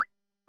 Pop Sound
A bright, clean cartoon pop like a bubble bursting or something appearing suddenly
pop-sound.mp3